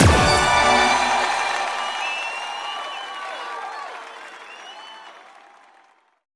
Win_Settle_Sound.mp3